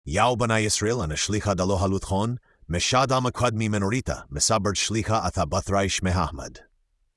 یک نسخه شبیه‌سازی‌شده را (بر اساس TTS)